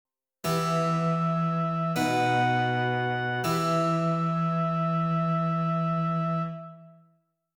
1.8 Dictations
Oboe &